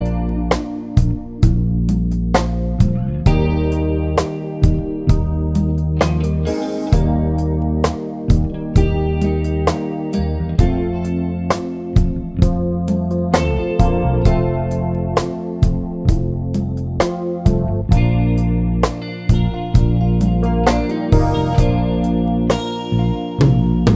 Live Pop (1960s) 4:21 Buy £1.50